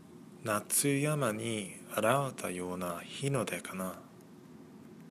Poème